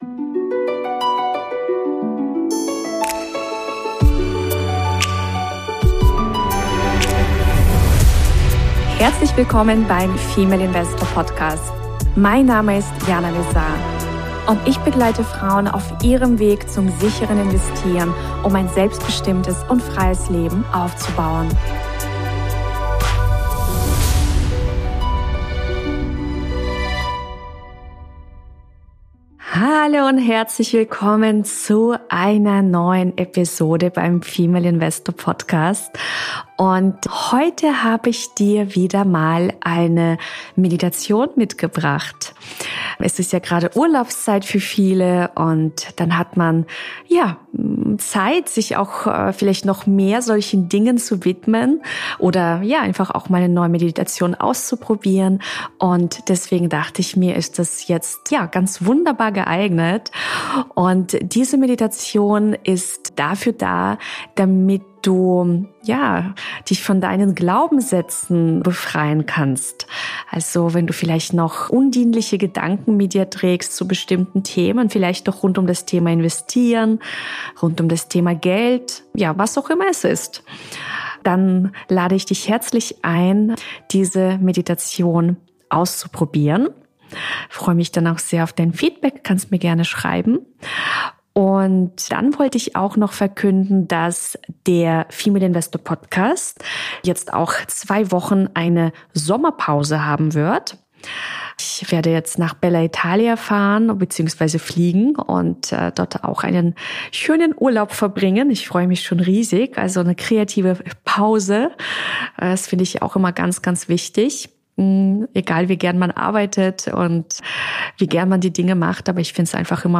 #169: Geführte Meditation: Befreie dich von deinen Glaubenssätzen ~ Female Investor Podcast